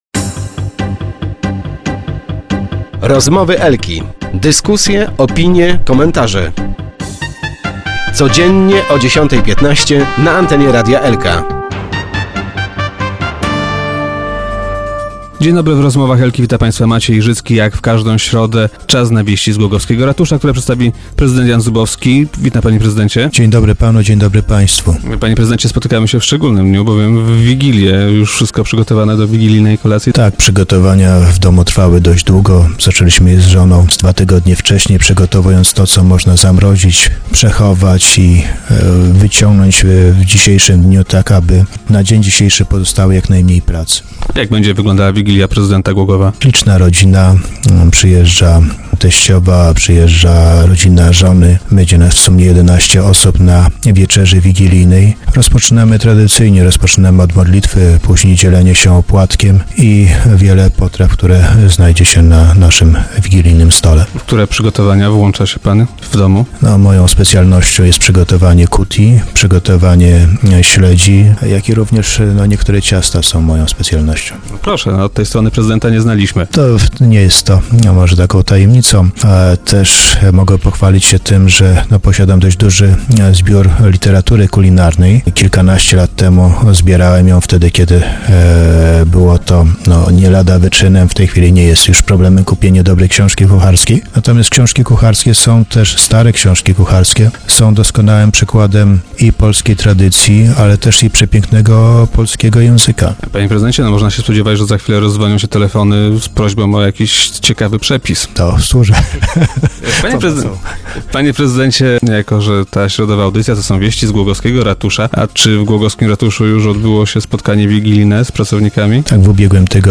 Stare książki kucharskie są doskonałym przykładem polskiej tradycji, oraz pięknego, polskiego języka - mówił dziś prezydent Zubowski na antenie Radia Elka.